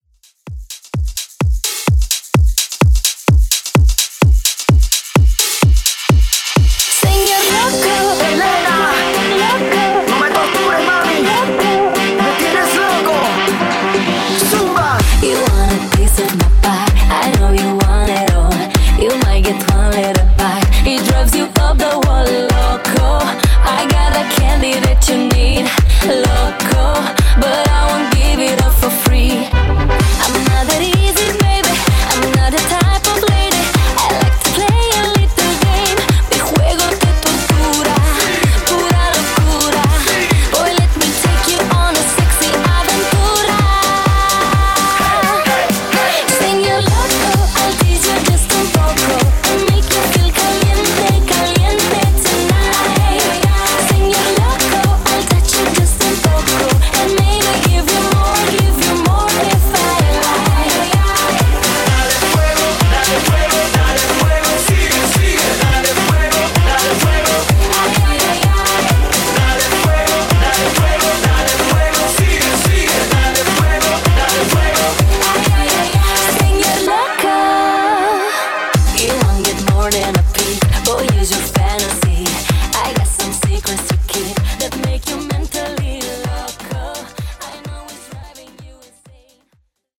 Genres: AFROBEAT , DANCE
Clean BPM: 122 Time